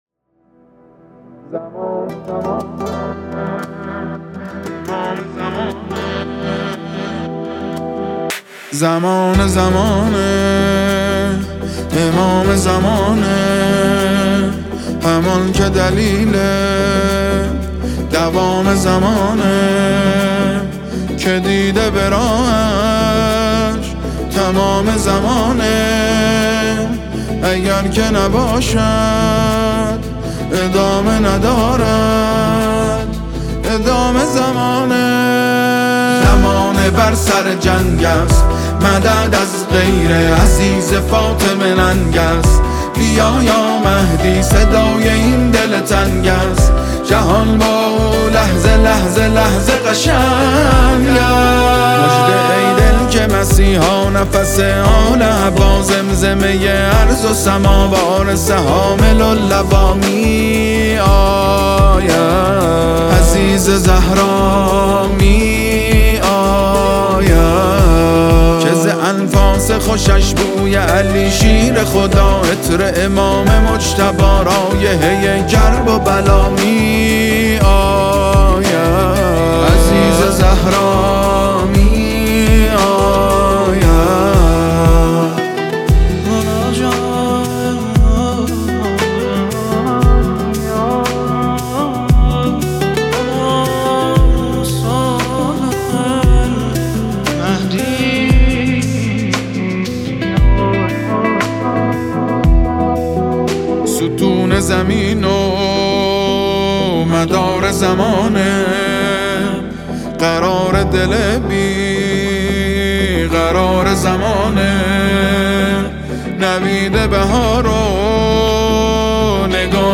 نماهنگ احساسی و دلنشین